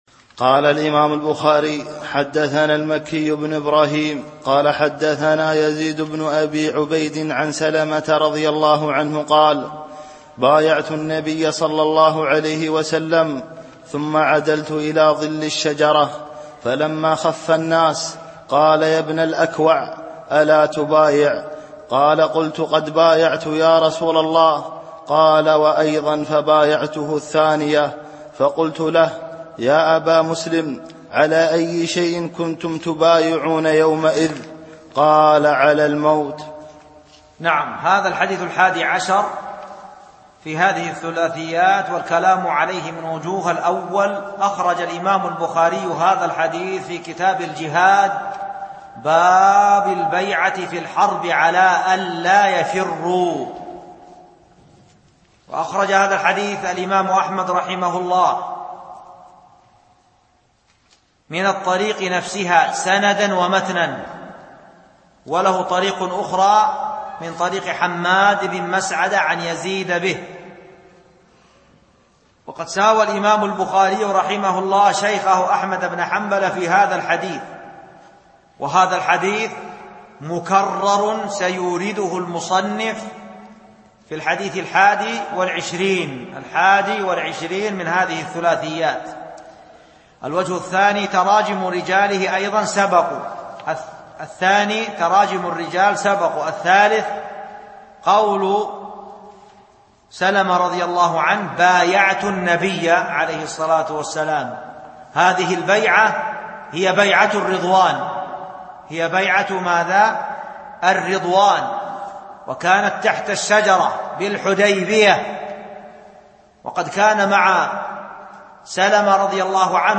الألبوم: شبكة بينونة للعلوم الشرعية المدة: 7:02 دقائق (1.65 م.بايت) التنسيق: MP3 Mono 22kHz 32Kbps (VBR)